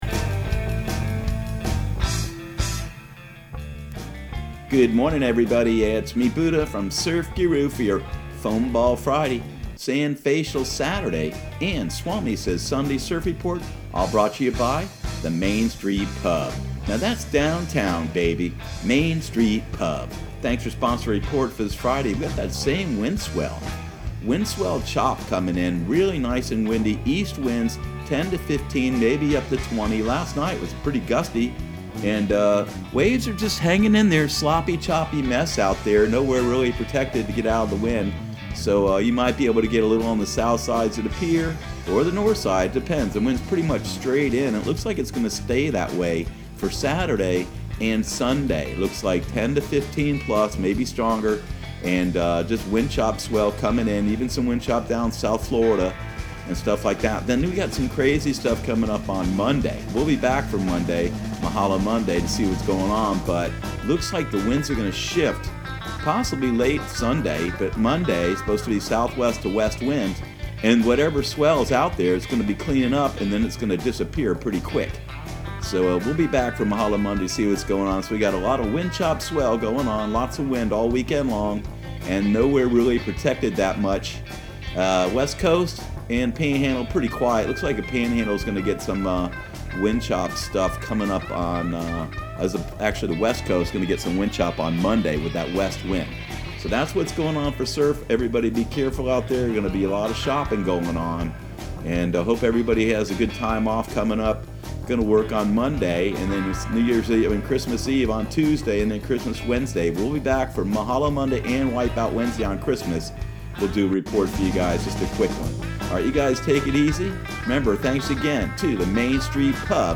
Surf Guru Surf Report and Forecast 12/20/2019 Audio surf report and surf forecast on December 20 for Central Florida and the Southeast.